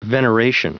Prononciation du mot veneration en anglais (fichier audio)
Prononciation du mot : veneration